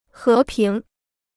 和平 (hé píng) Free Chinese Dictionary